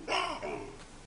Kri   Chacma baboon
Baboon_Bark.ogg